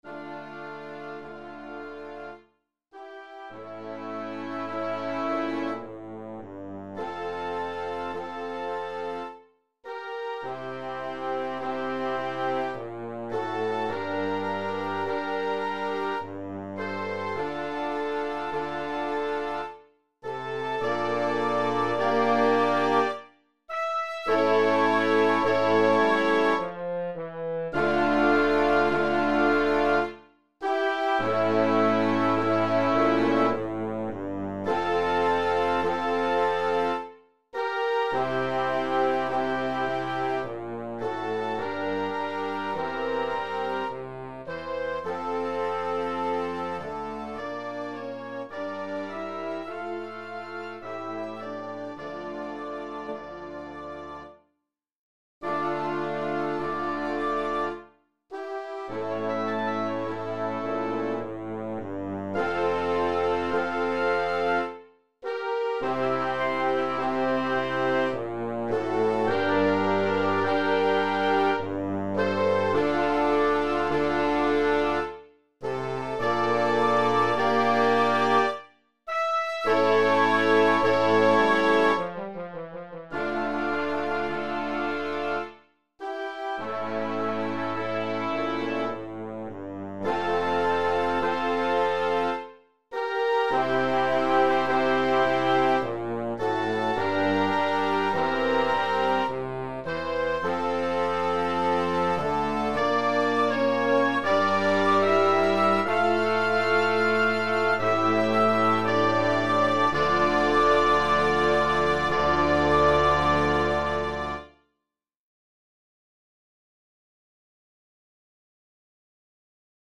I just learned of this today and came home after school and arranged this full band piece in his honor.
sarabande-m.mp3